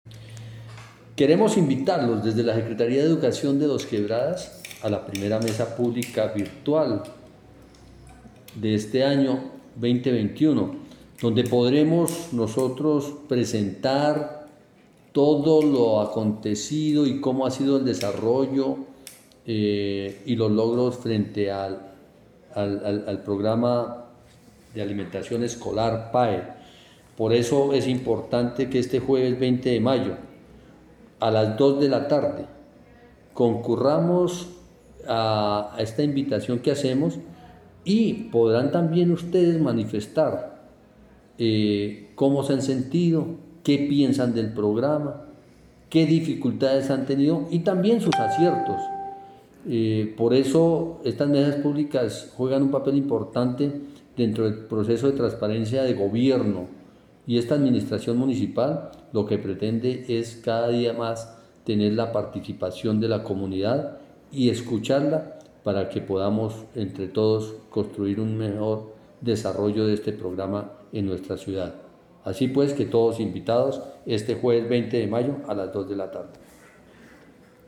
Comunicado-247-Audio-Secretario-de-Educacion-Celso-Omar-Parra.mp3